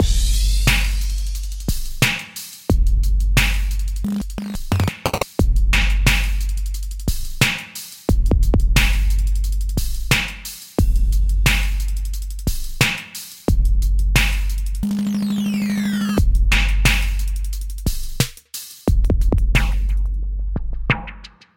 Tag: 89 bpm Hip Hop Loops Drum Loops 3.63 MB wav Key : Unknown